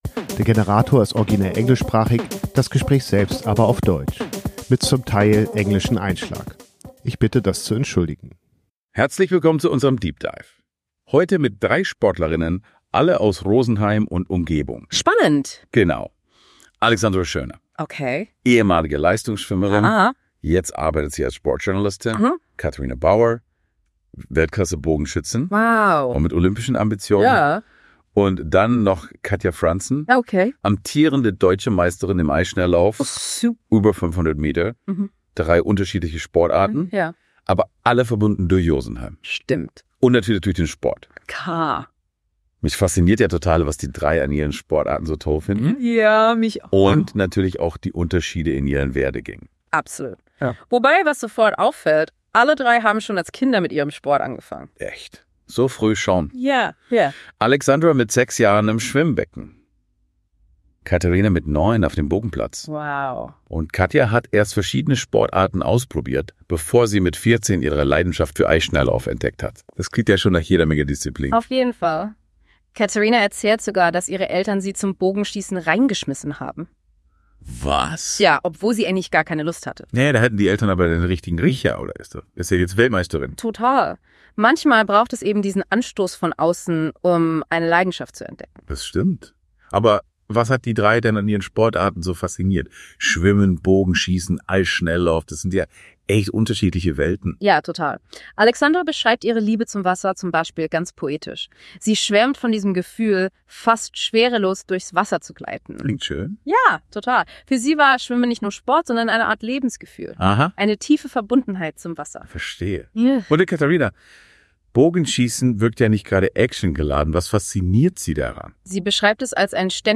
Diese habe ich thematisch sortiert und mit einer KI remixed. Die KI hat allerdings Schwierigkeiten mit bayerischen Idiomen.